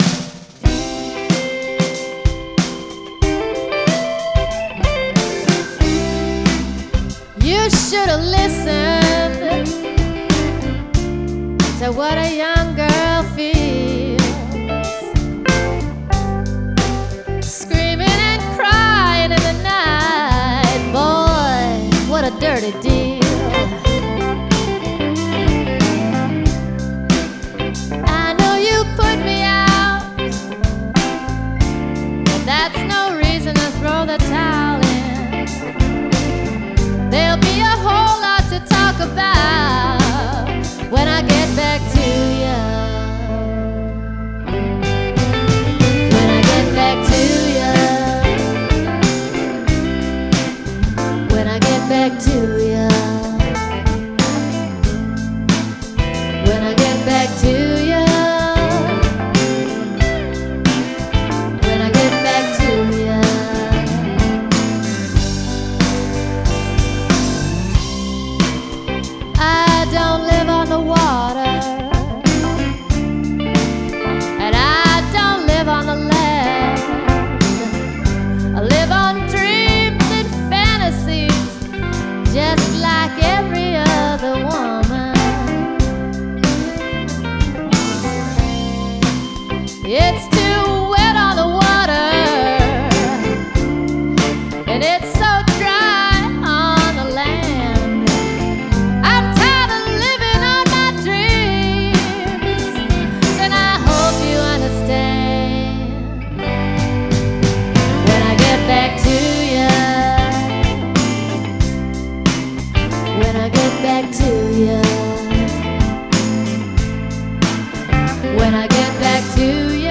blues/roots